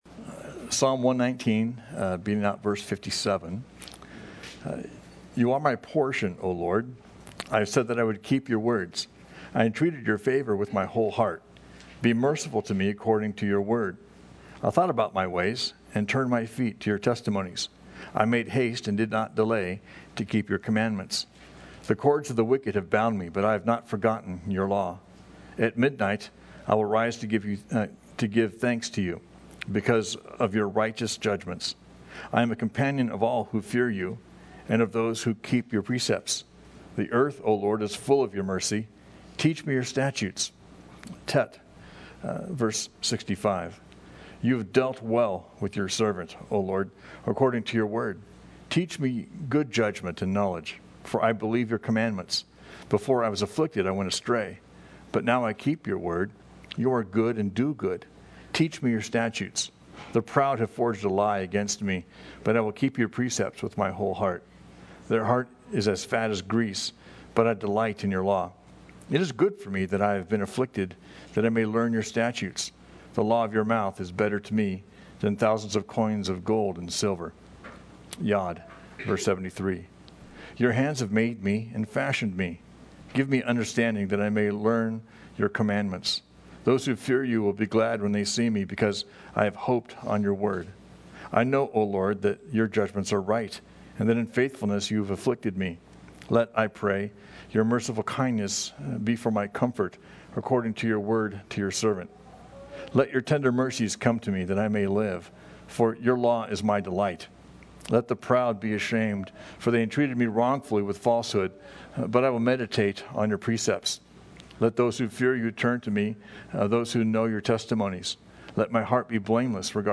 We livestream all of our services on FaceBook.